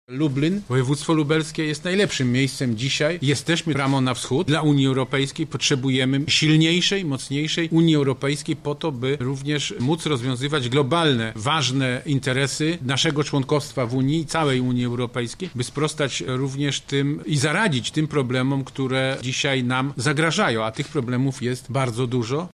To właśnie Lublin jest miejscem w którym można rozmawiać o przyszłości wschodniego partnerstwa z którym miasto od lat współpracuje a Wspólnotą Europejską. – mówi prof. Jerzy Buzek, Poseł do Parlamentu Europejskiego
Spotkanie na którym dyskutowano o przyszłości wschodniego partnerstwa, a Wspólnotą Europejską było kolejnym z wydarzeń organizowanych w ramach Kongresu Inicjatyw Europy Wschodniej.